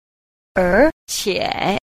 3. 而且 – érqiě – nhi thả (và, hơn nữa)